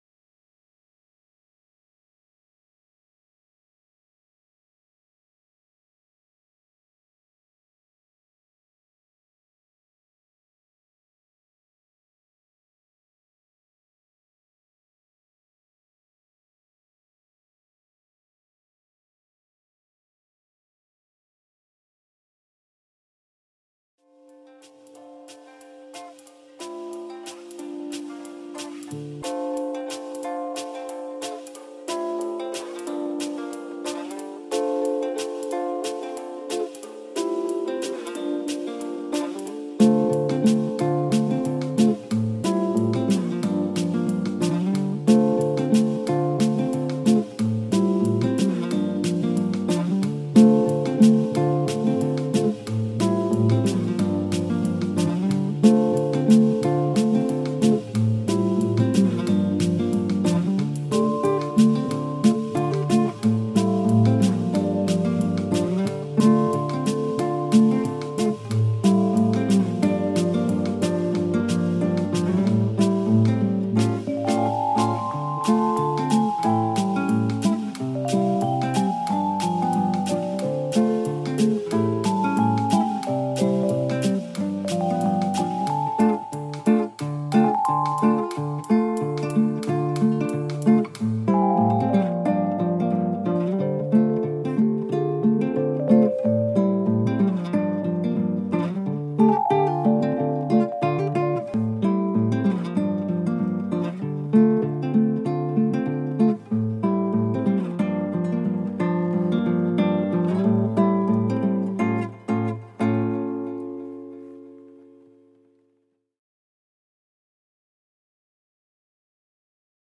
Live Q&A - 88 - The Hierarchy Is Externalised